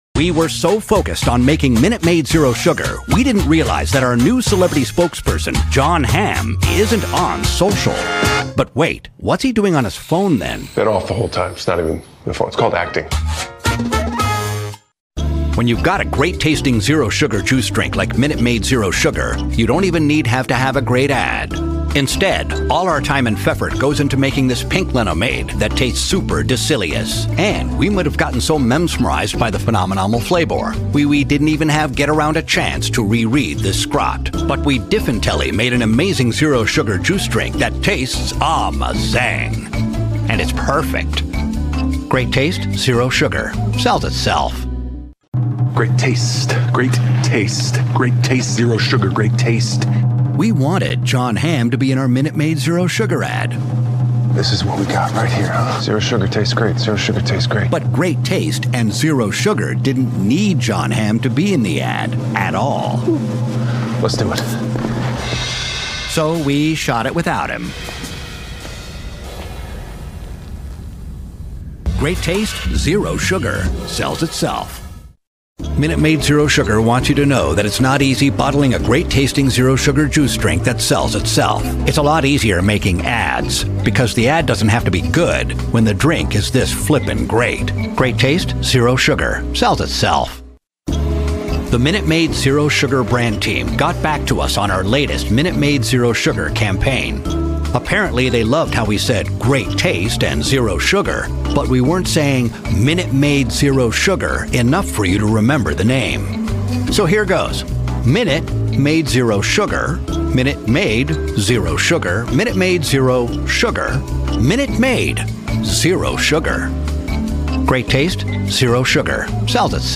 Male
English (North American)
Adult (30-50), Older Sound (50+)
Television Spots